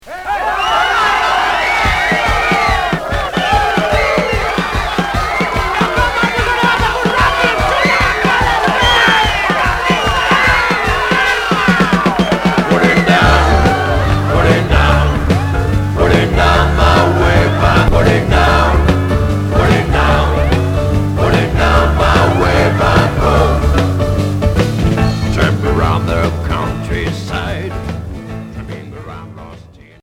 Pop Deuxième 45t retour à l'accueil